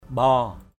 /ɓɔ:/ 1. (t.) vun, đầy ắp = plein à déborder. brimful. pangin lasei mbaow pz{N ls] _O<w chén cơm vun = le bol est plein à déborder. the bowl is...